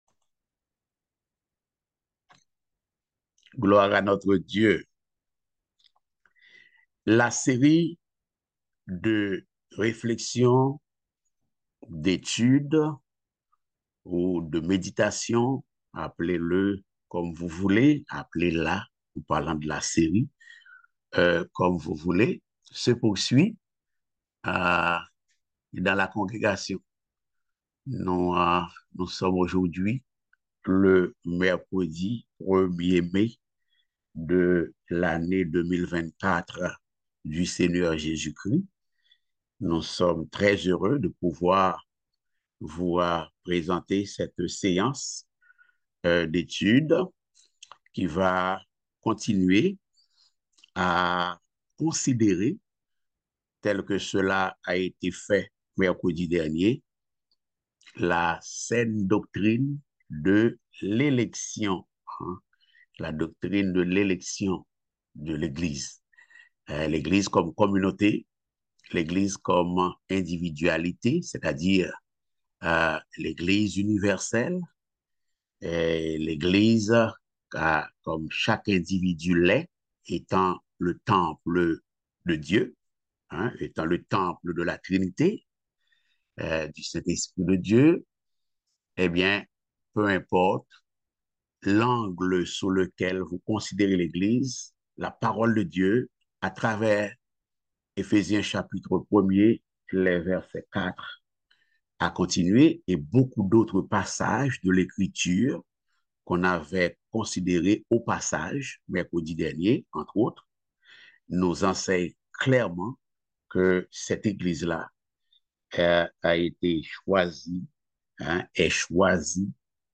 Éphésiens 1.4-5 Type De Service: Études Bibliques « Le choix et l’établissement inconditionnels des disciples Voir les relations conjugales au sein du foyer comme Dieu les voit.